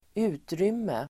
Uttal: [²'u:trym:e]